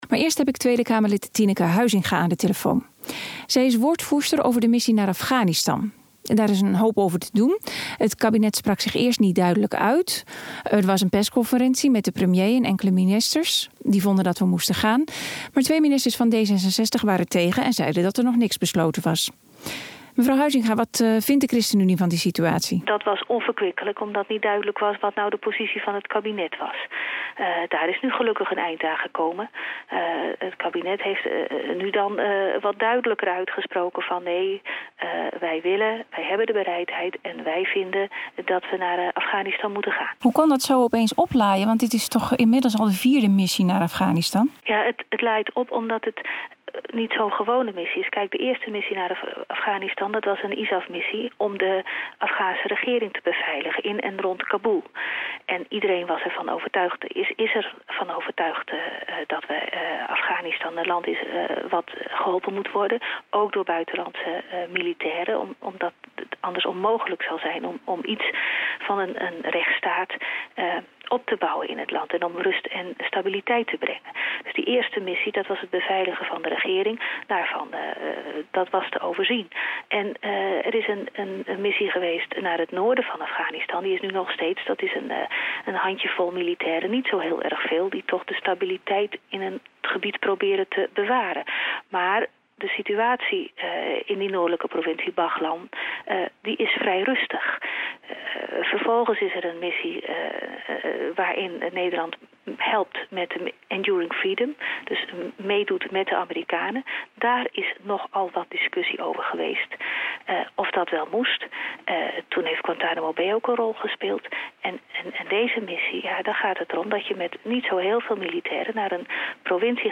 Interview 96 kbit